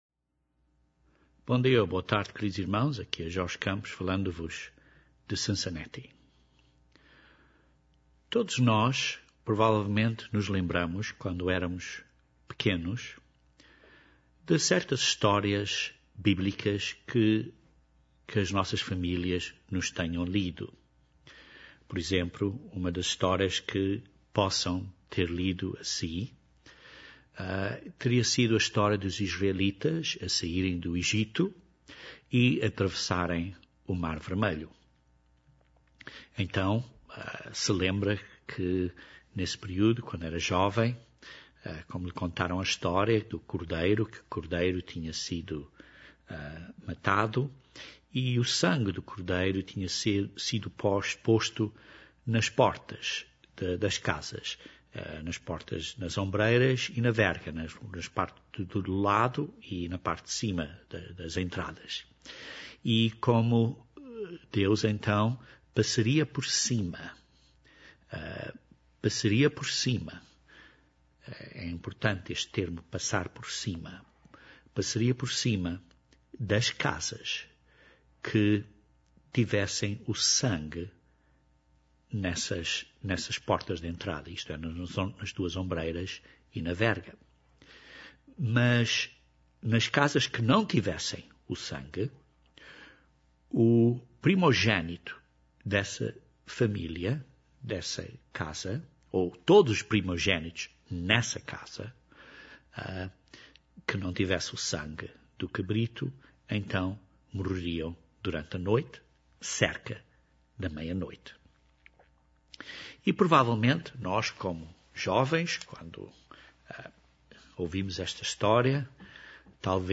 Este sermão descreve este tema em maior detalhe.